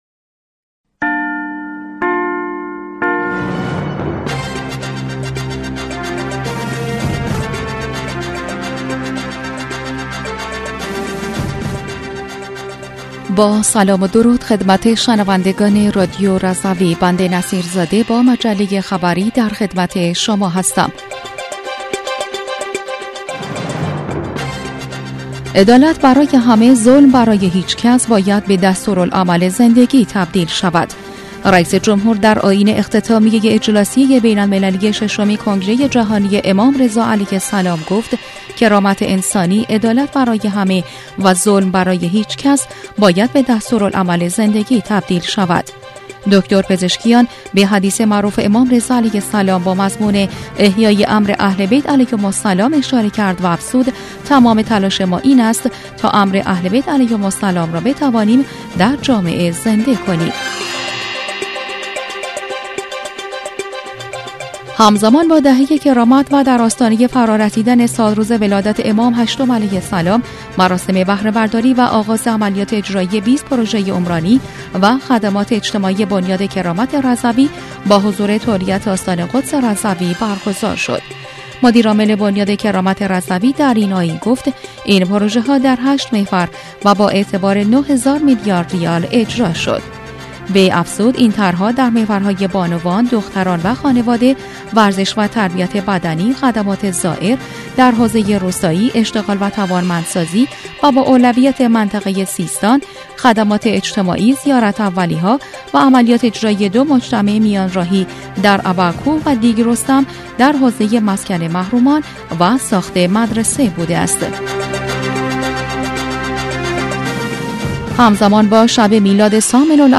بسته خبری 18اردیبهشت رادیو رضوی؛